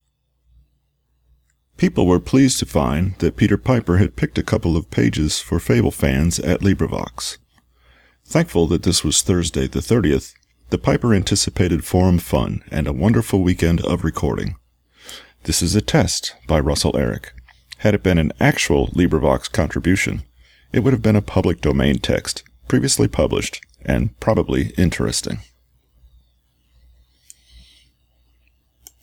Starting with the One Minute test, which is basically a tongue twister with as many hard and breathy consonants in it as possible – it reads thusly:
So, all the P’s, F’s, S’s and Th’s in there are to see if your recording is free enough of plosives to not annoy the average listener.